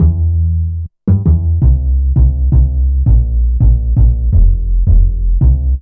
Index of /90_sSampleCDs/Zero-G - Total Drum Bass/Instruments - 1/track02 (Bassloops)